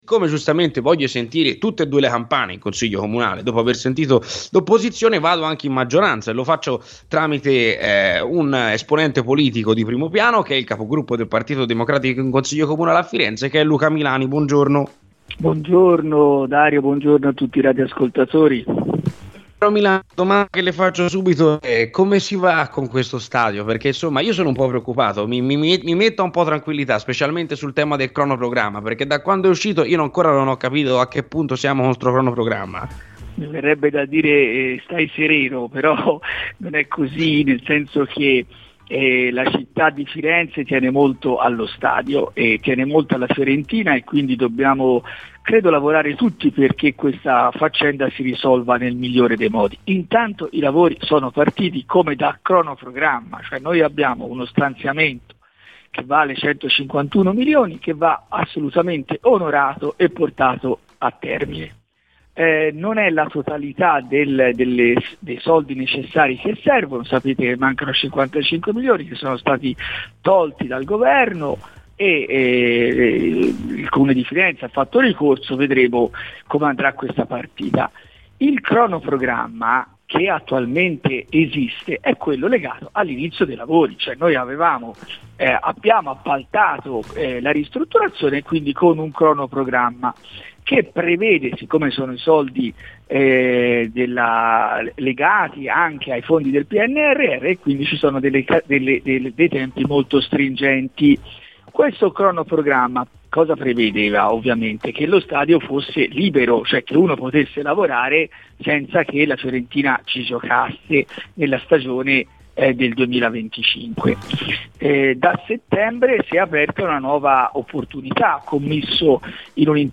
Luca Milani, capogruppo PD in Consiglio Comunale a Firenze, è intervenuto oggi a Radio Firenzeviola, durante "C'è polemica" spiegando: "Cronoprogramma sul Franchi? Verrebbe da dire di stare sereni.